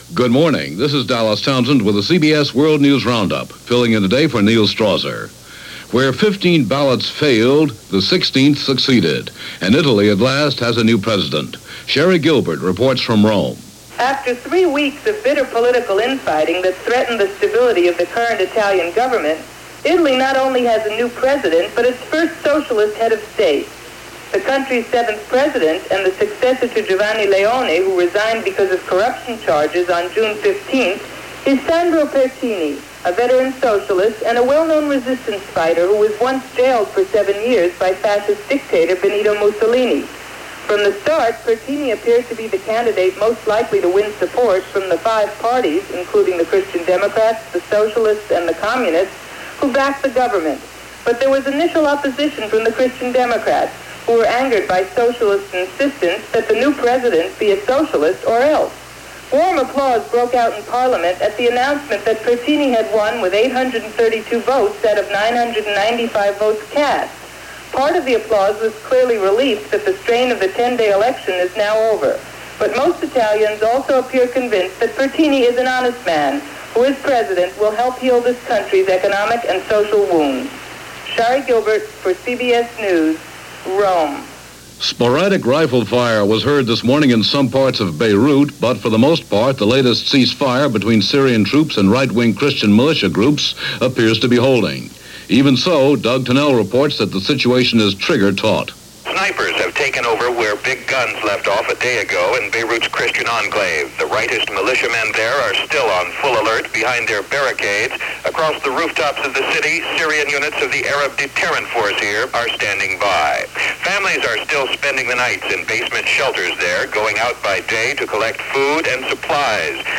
And that’s a small slice of what happened, this July 8, 1978 as presented by The CBS World News Roundup.